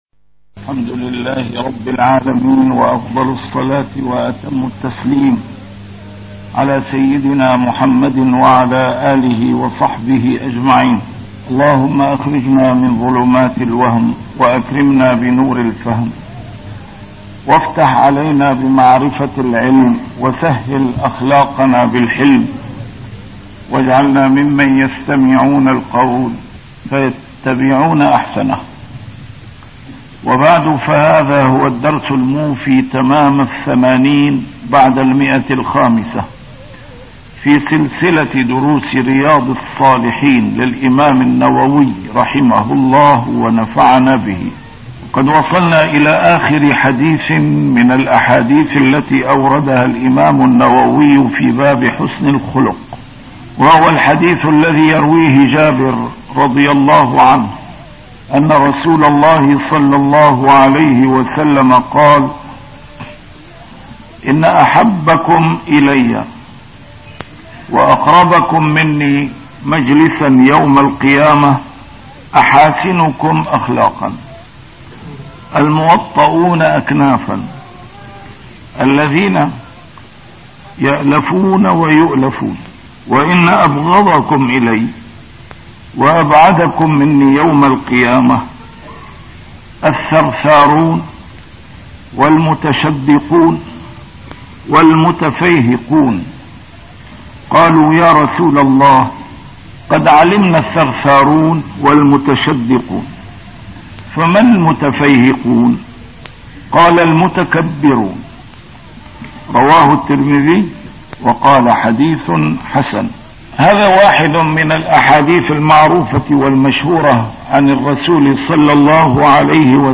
A MARTYR SCHOLAR: IMAM MUHAMMAD SAEED RAMADAN AL-BOUTI - الدروس العلمية - شرح كتاب رياض الصالحين - 580- شرح رياض الصالحين: حسن الخلق